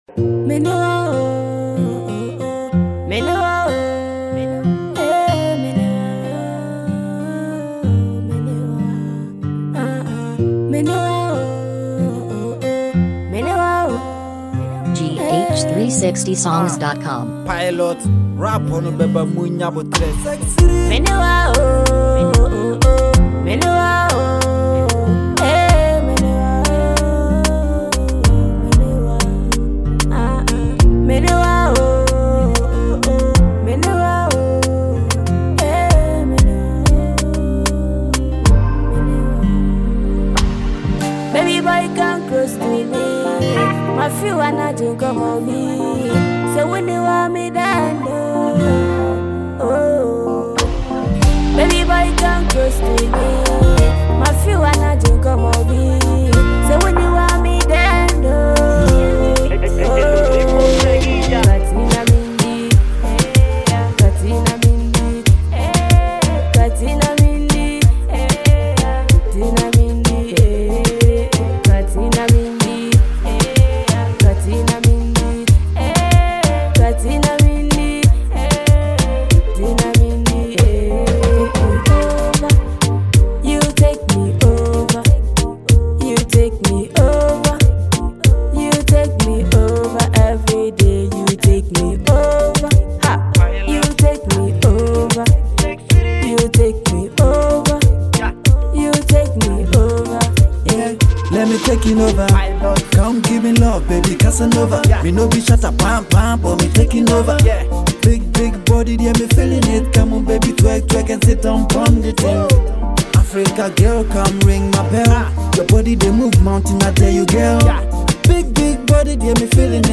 Ghana Music Music
love song